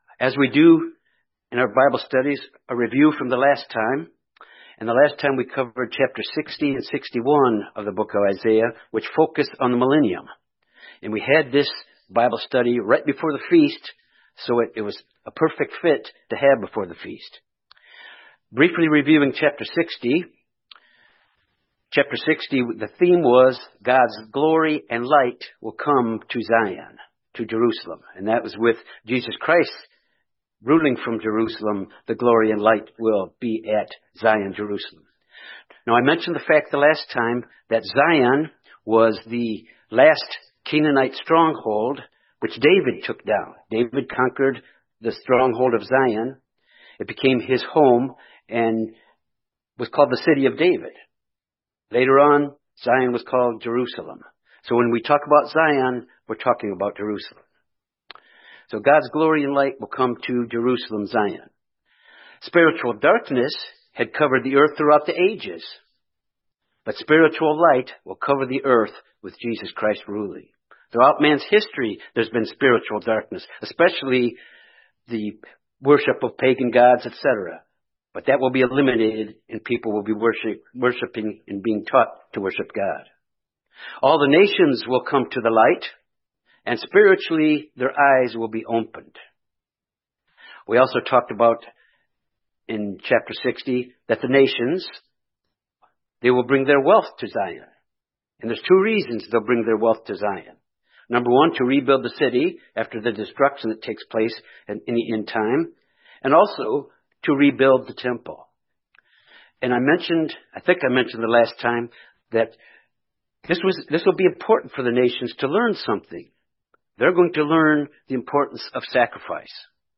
This Bible study examines chapters 60-61 of the book of Isaiah.